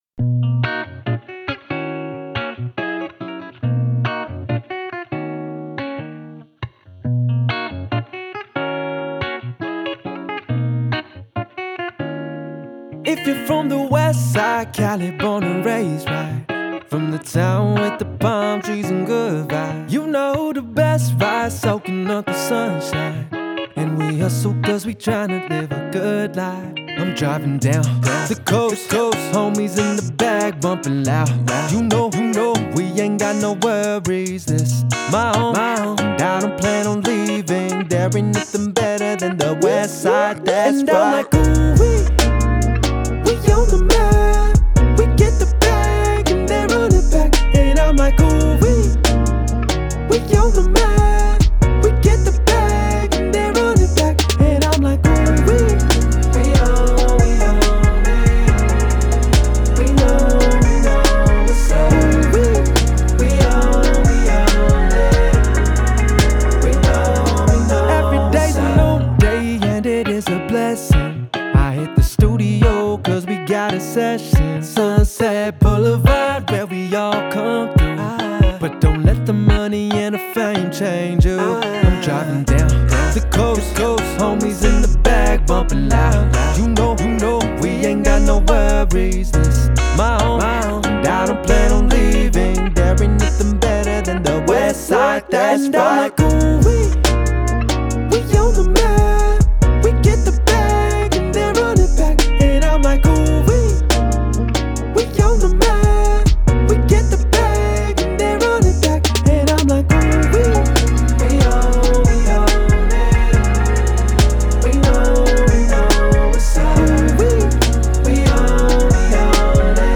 Genre: Talk Show
Through insightful discussions, real-world examples, and expert guest interviews, we aim to illuminate the paths forward in a time of crisis.